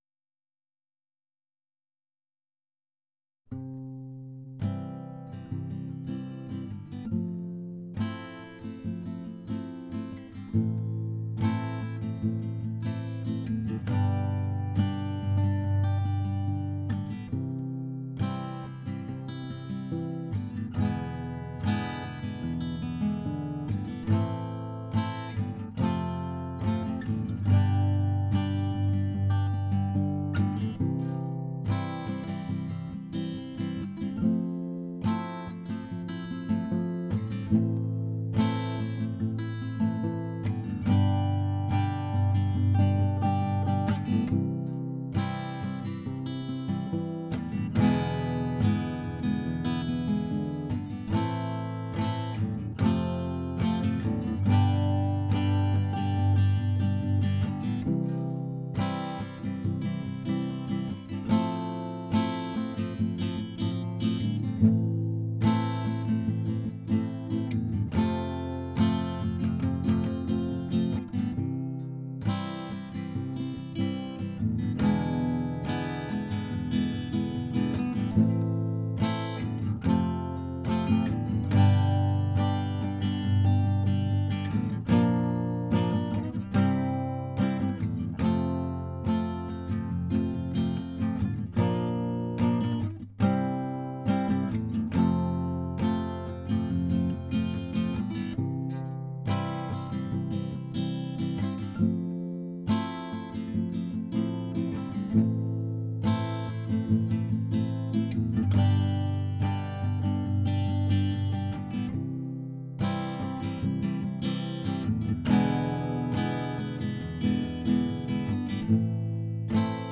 acousticguitar.wav